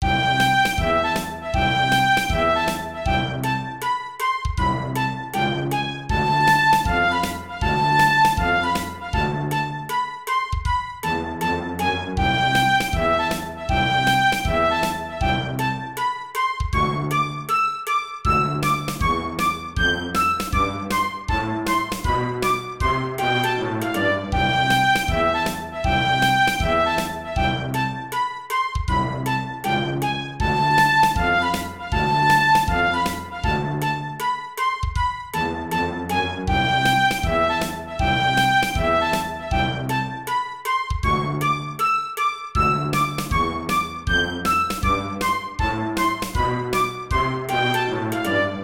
Tag Archives: 明るい
フルート、オーボエ、バイオリンが何だか幸せなメロディーを奏でます。
ショートループ